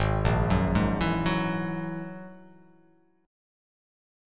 G#7sus2#5 chord